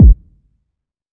• Long Room Reverb Bass Drum Sample G Key 208.wav
Royality free kick drum one shot tuned to the G note. Loudest frequency: 99Hz
long-room-reverb-bass-drum-sample-g-key-208-B8i.wav